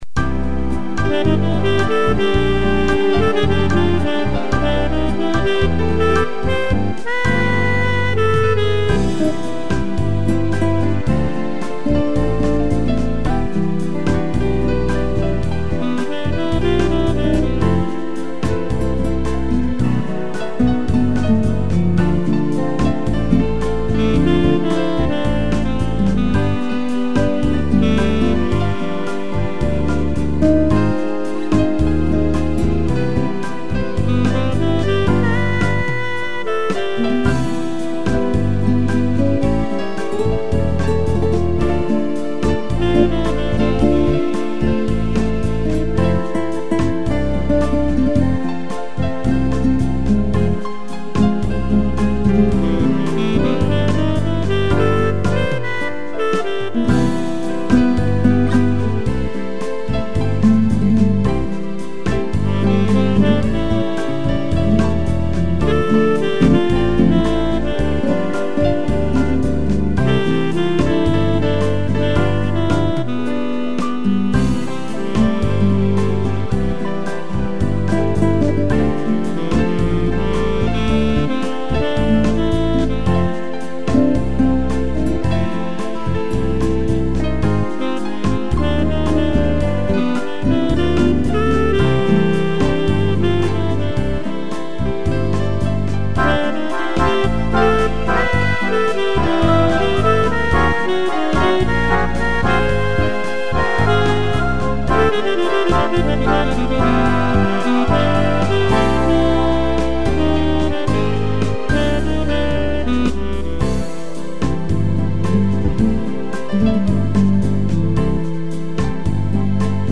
piano e sax
(instrumental)